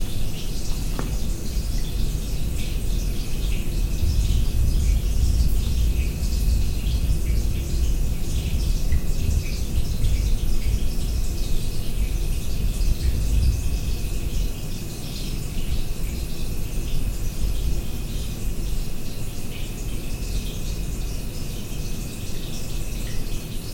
随机 " 下水道排水管关闭2
Tag: 漏极 下水道 关闭